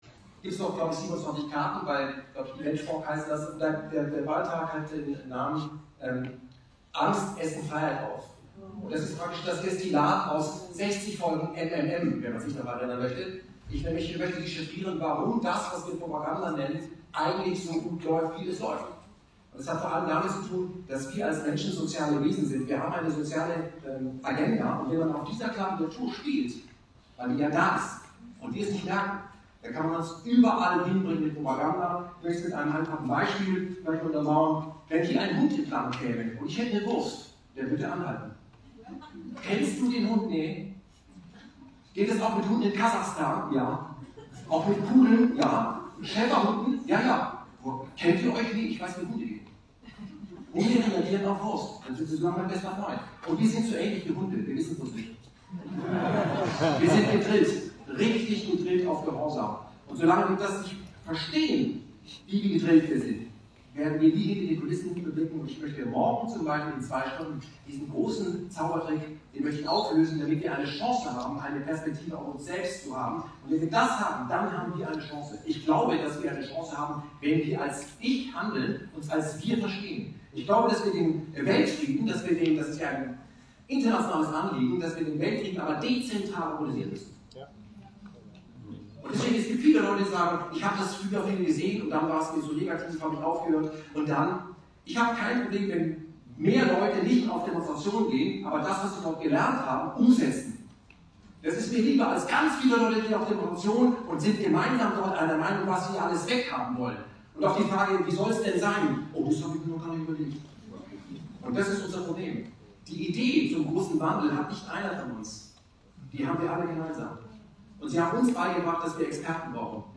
Wer die Techniken der permanenten Propaganda durchschaut, kann sich erfolgreich gegen die eigene Manipulation wehren - angstfrei! Kayvan Soufi-Siavash war in Zürich und präsentierte sein neues Soloprojekt.